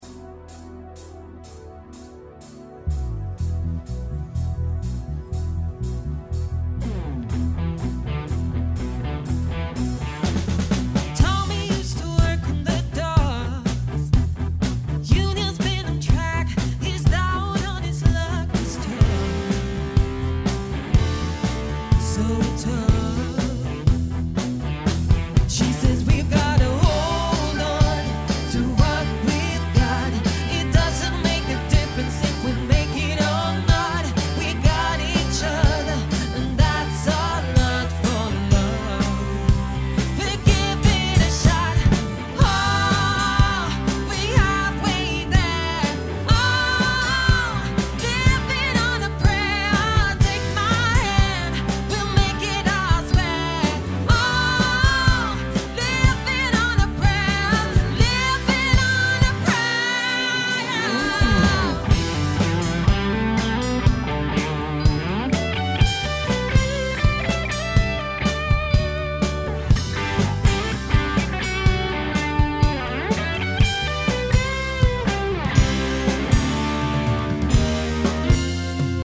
party/cover band
fem musiker